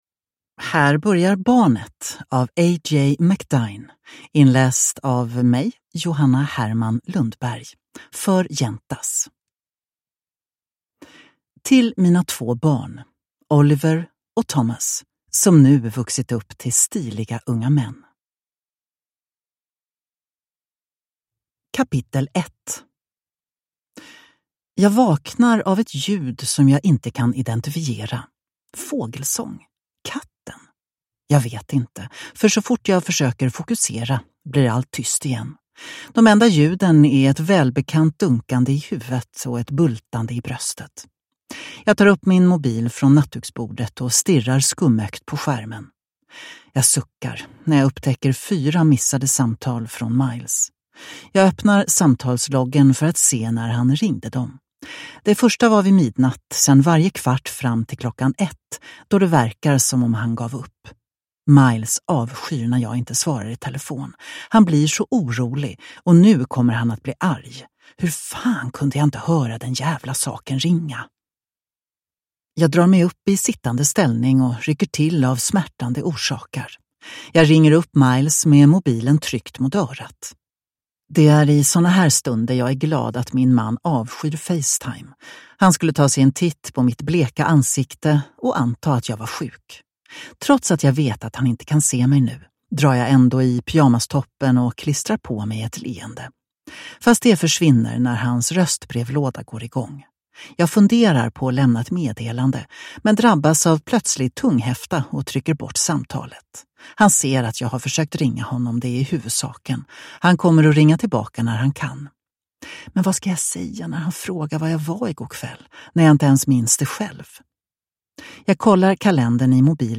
Barnet – Ljudbok